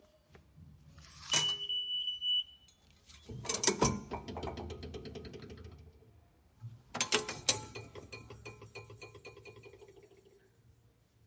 Звуки турникета
Звук оплаты карты в турникете метро